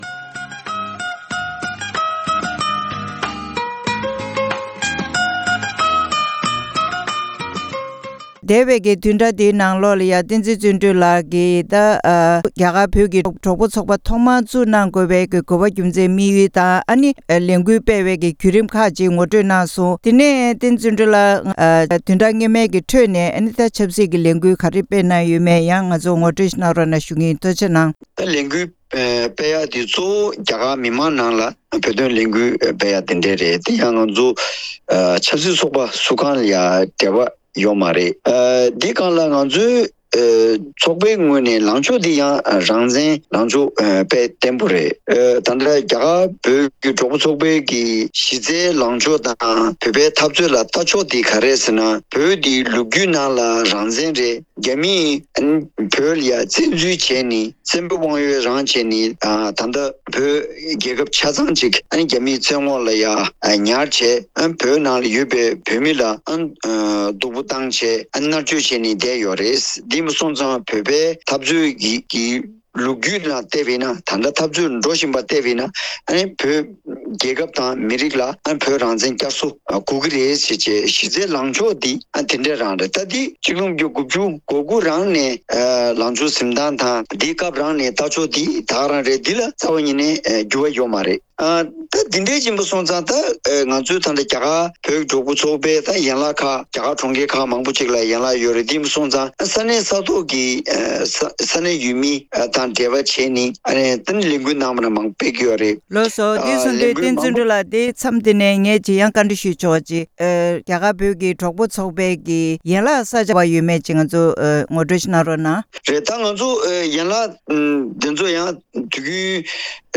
གནས་འདྲི་ཞུས་པ་ཞིག་གསན་གནང་གི་རེད།